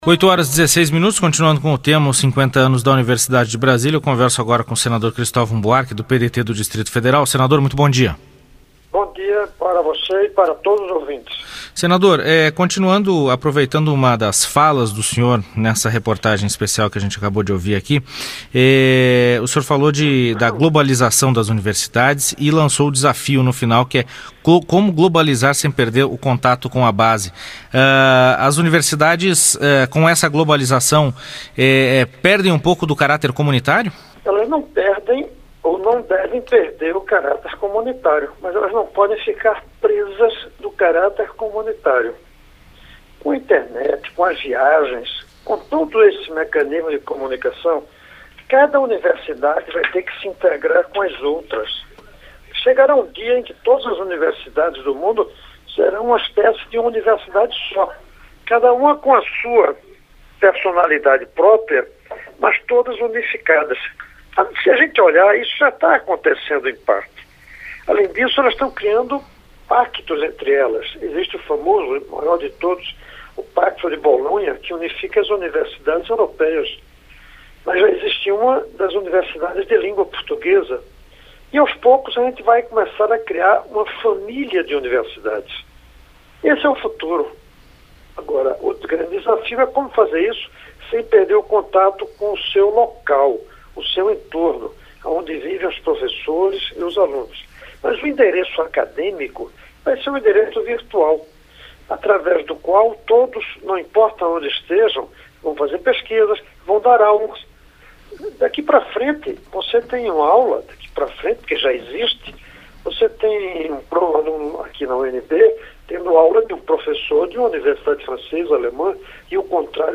Entrevista com o senador Cristovam Buarque (PDT-DF).